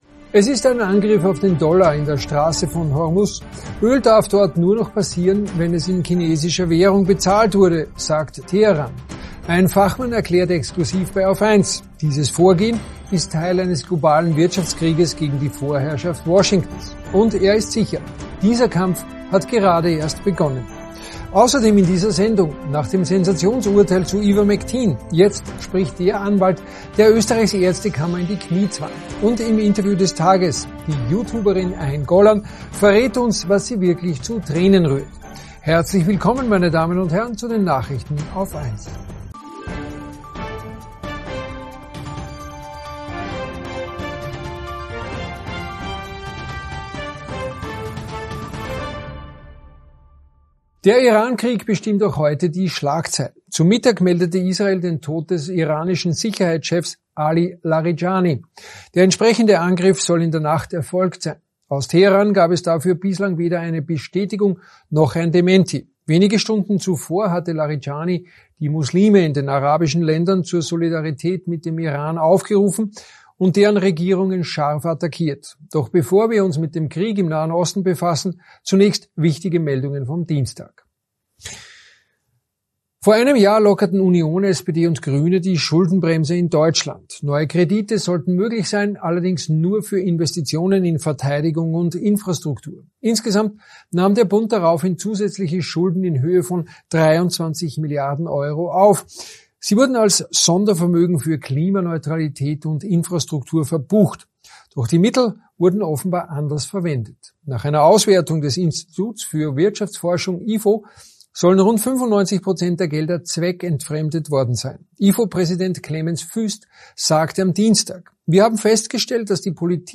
Nachrichten AUF1 vom 17. März 2026 ~ AUF1 Podcast